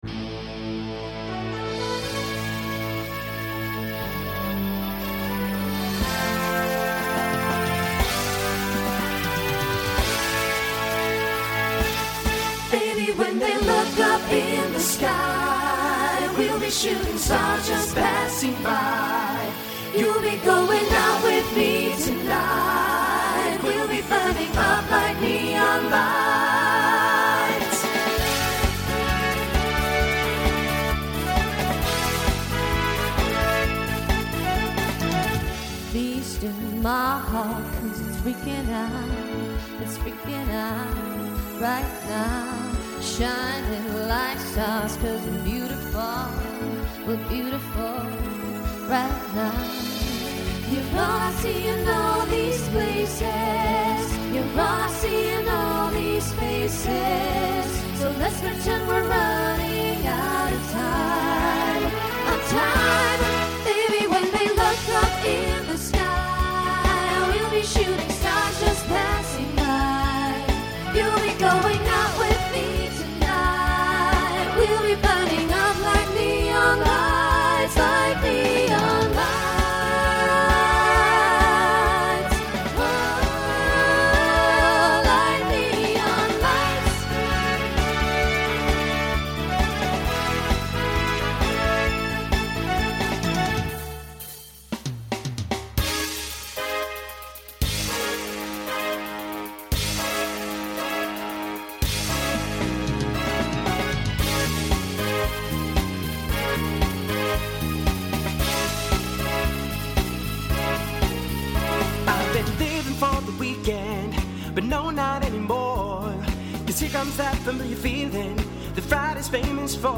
Voicing SATB Instrumental combo Genre Pop/Dance